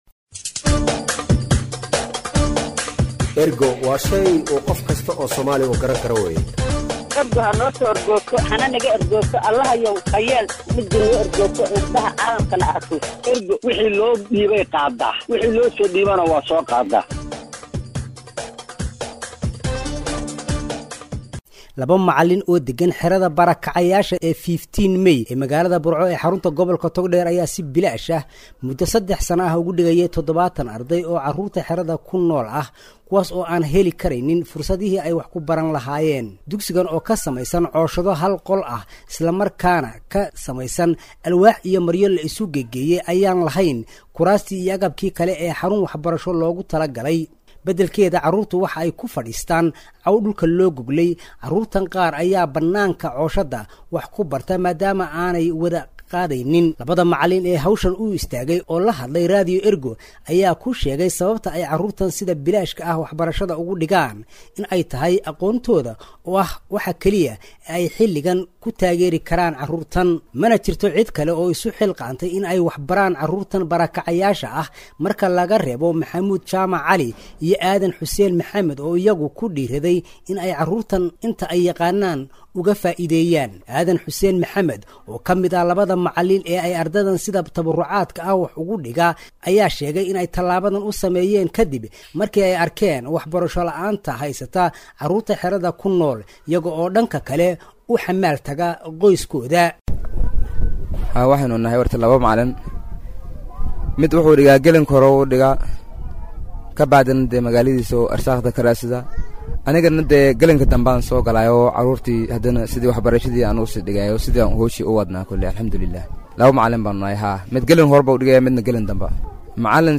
Warbixin arrintaas ku saabsan waxaa magaalada Burco innooga soo diray